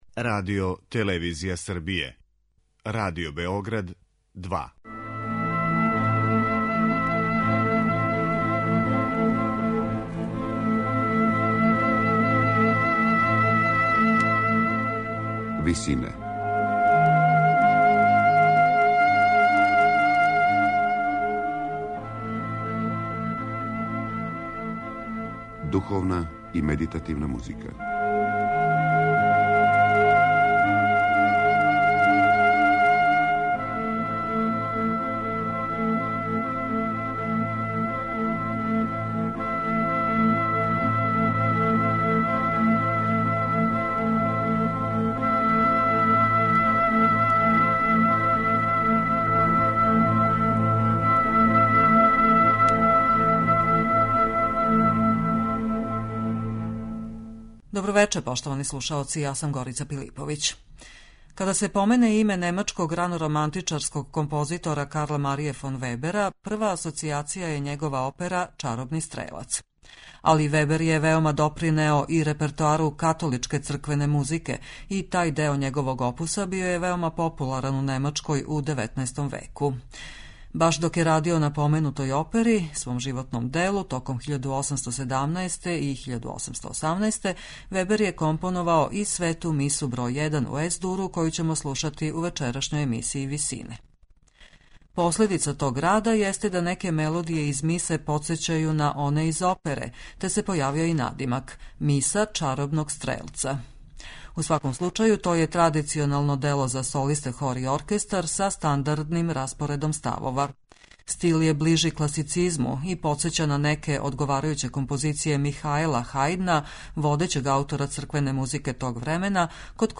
Миса аутора Чаробног стрелца
Али Вебер је веома допринео и репертоару католичке црквене музике
медитативне и духовне композиције